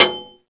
metalsolid10.wav